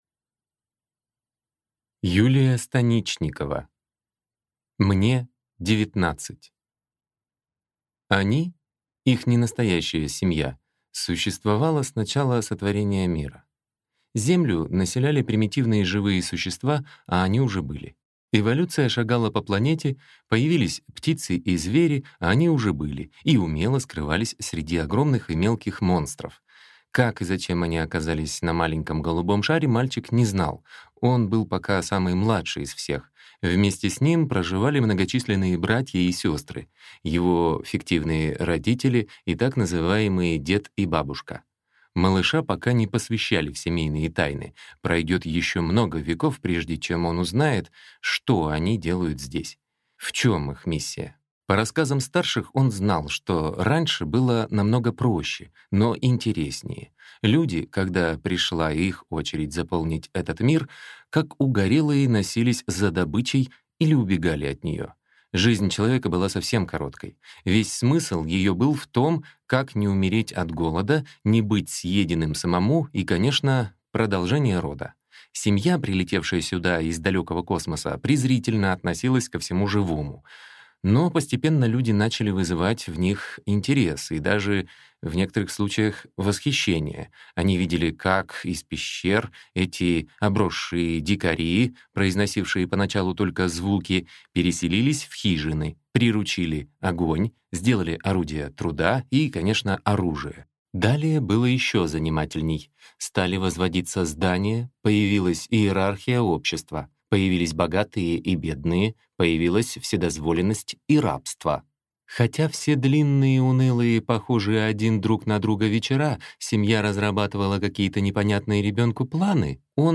Аудиокнига Мне 19 | Библиотека аудиокниг
Прослушать и бесплатно скачать фрагмент аудиокниги